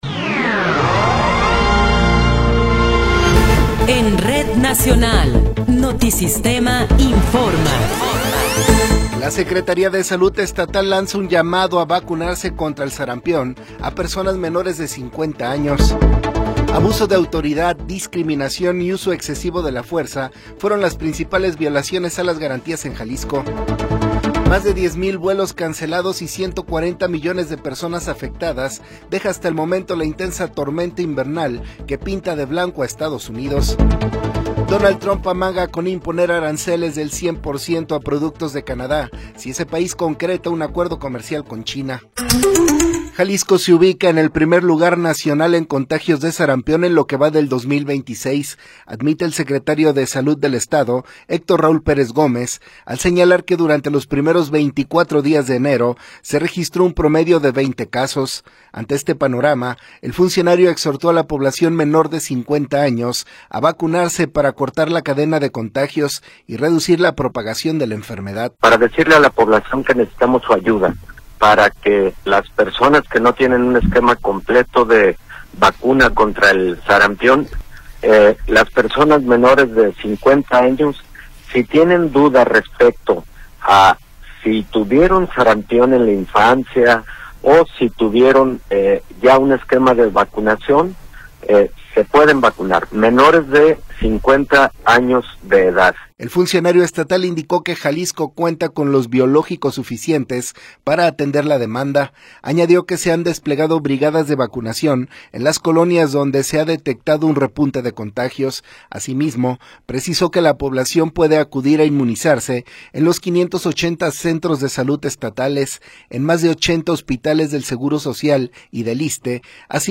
Noticiero 14 hrs. – 24 de Enero de 2026
Resumen informativo Notisistema, la mejor y más completa información cada hora en la hora.